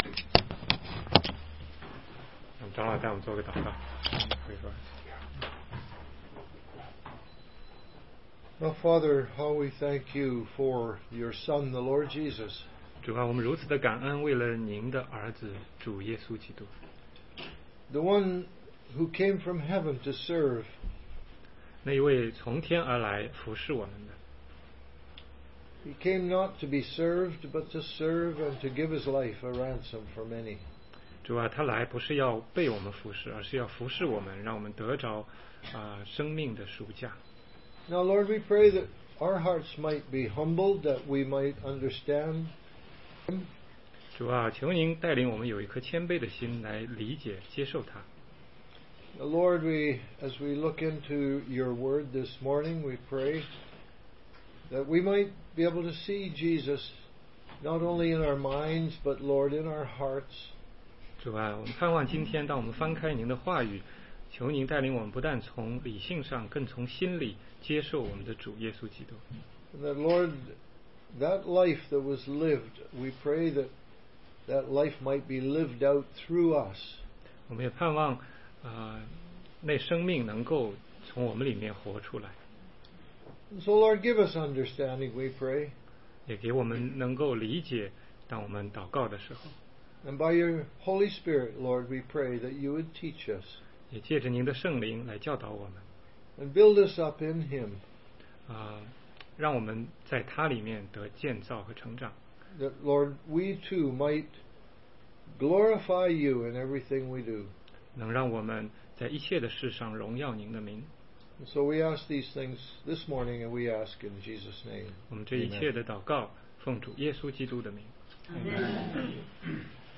16街讲道录音 - 约翰福音